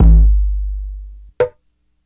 Boomconga 3.4 time 87bpm.wav